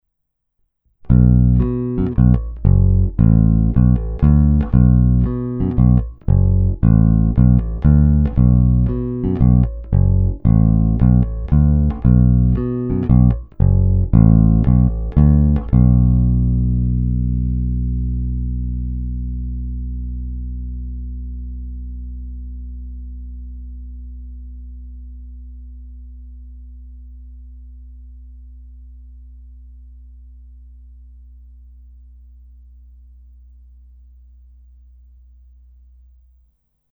Hra nad snímačem
Parádní tučný, pevný, zvonivý zvuk s těmi správnými středy, které tmelí kapelní zvuk a zároveň dávají base vyniknout.